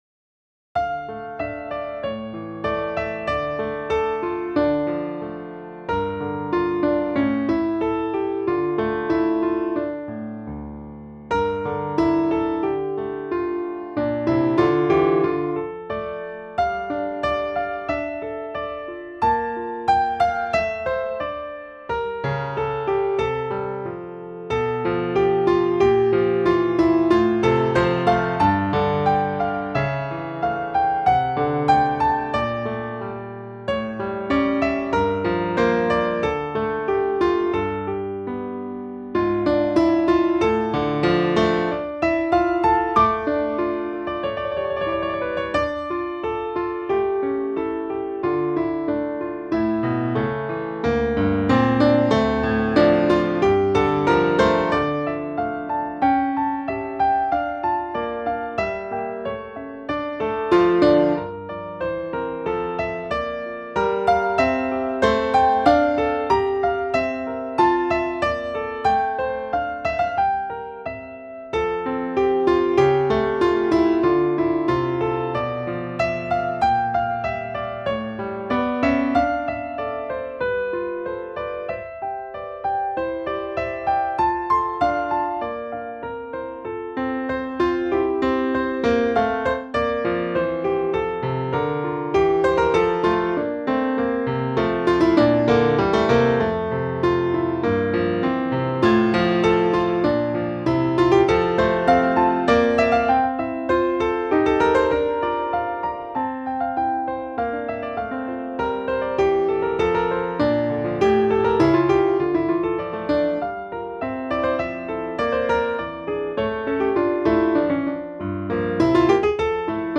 Piano Sonatinas Audio Gallery
Maene Vinoly Concert Grand Piano